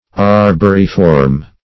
Arboriform \Ar*bor"i*form\, a.